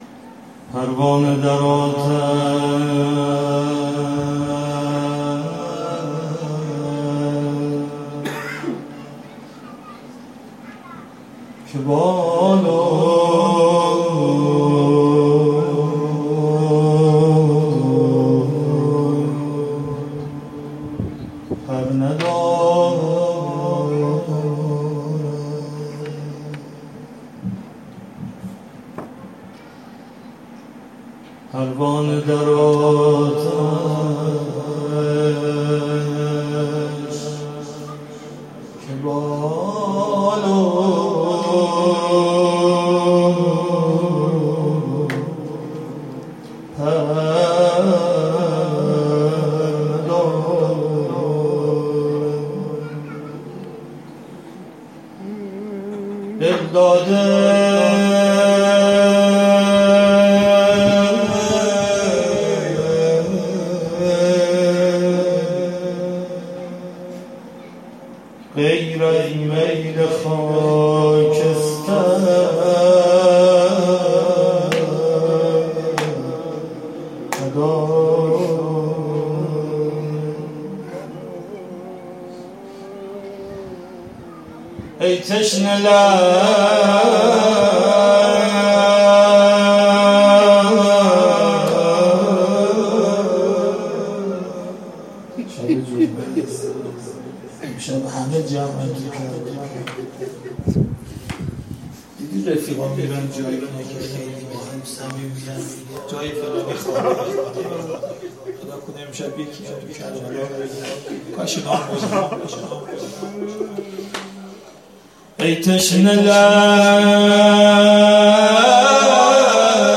آرشیو مراسمات هفتگی
شعر